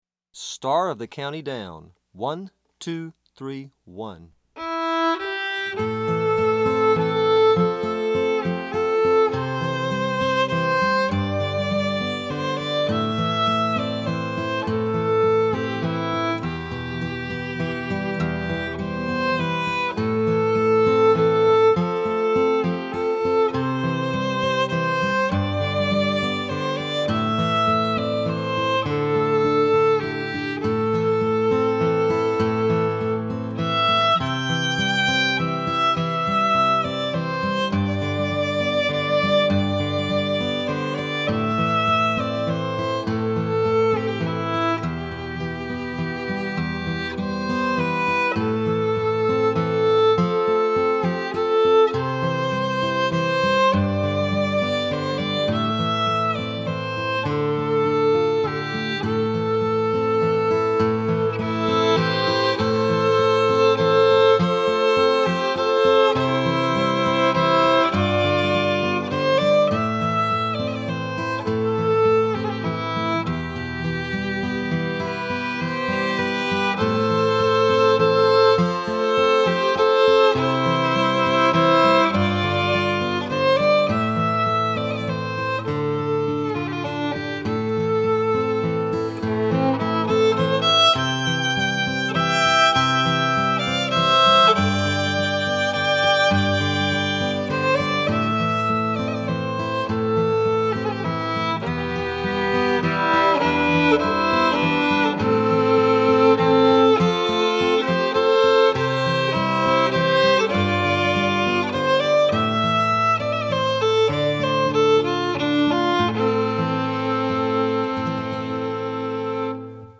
FIDDLE SOLO Fiddle Solo, Traditional
DIGITAL SHEET MUSIC - FIDDLE SOLO